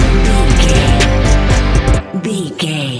Ionian/Major
ambient
electronic
new age
chill out
downtempo
pads